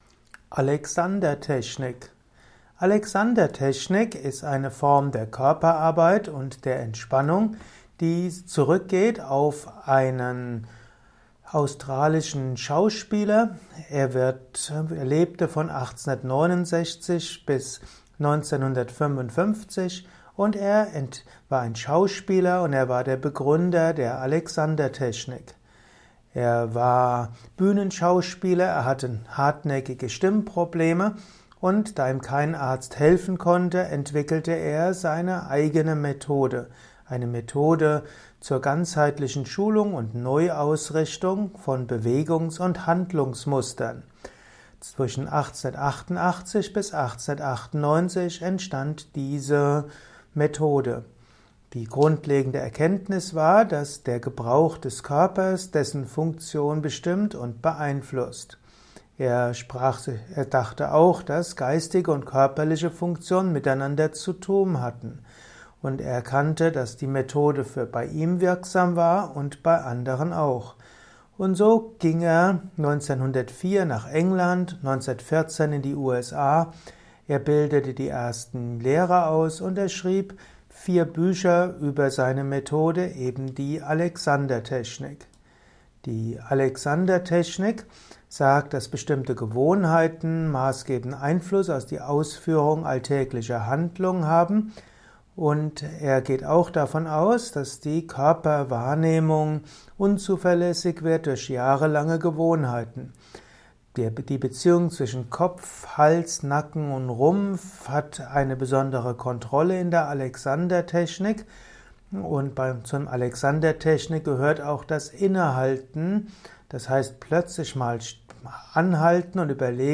Vortrags-Podcast.